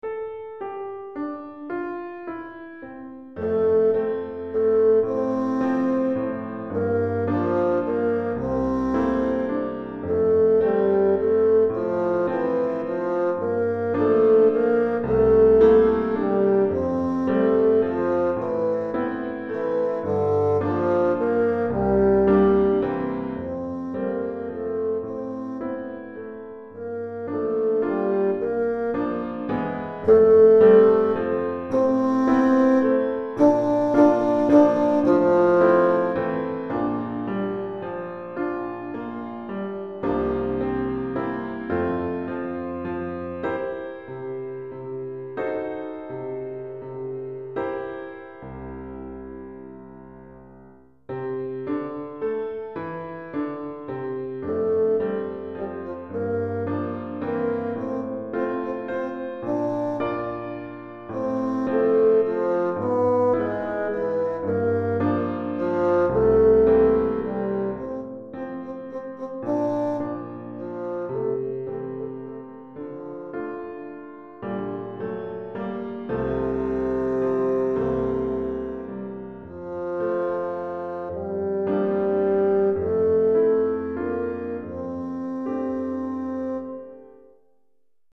Basson et Piano